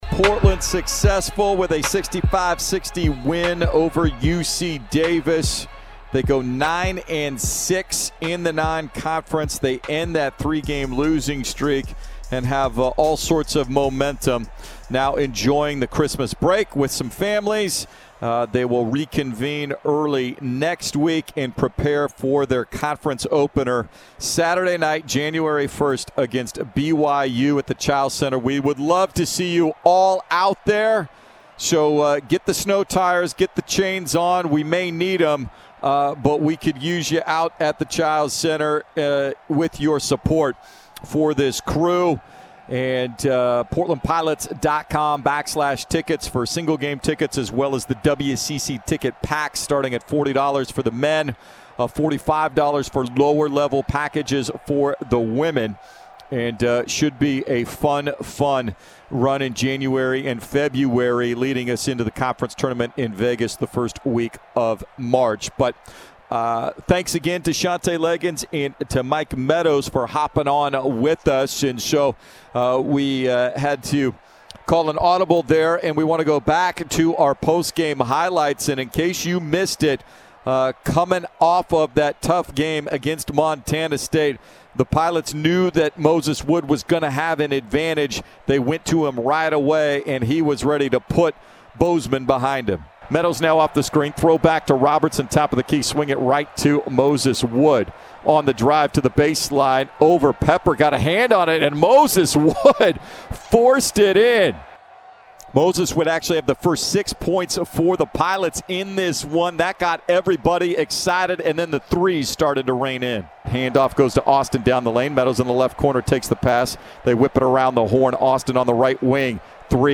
Men's Hoops Radio Highlights vs. UC Davis